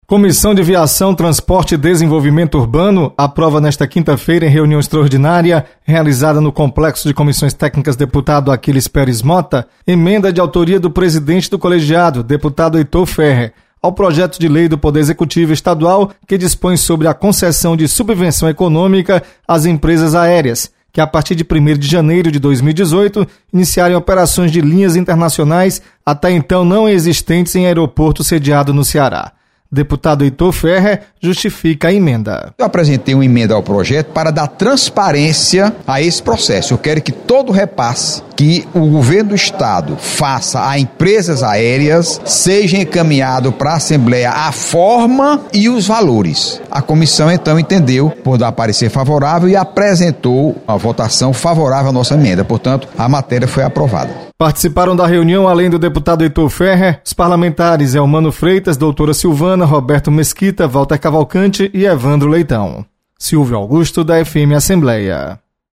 Comissão de Viação, Transporte e Desenvolvimento Urbano realiza votação de projetos, nesta quinta-feira. Repórter